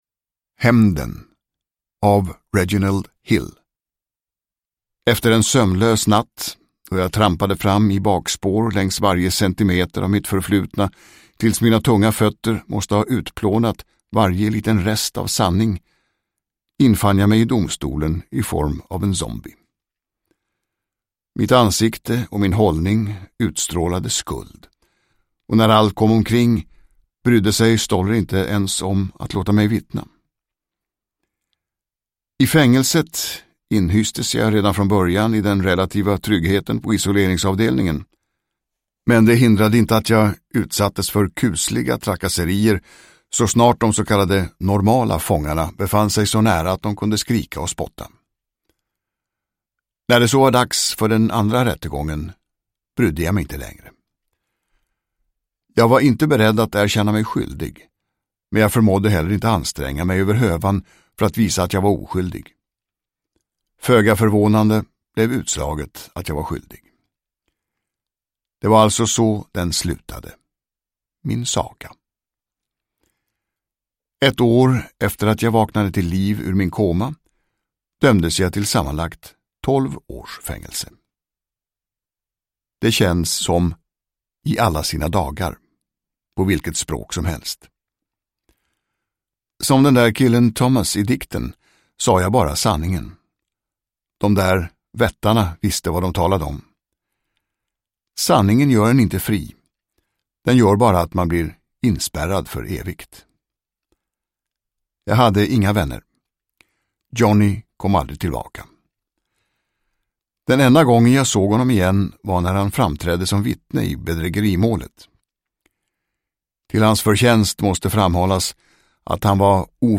Hämnden – Ljudbok – Laddas ner
Uppläsare: Tomas Bolme